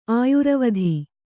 ♪ āyuravadhi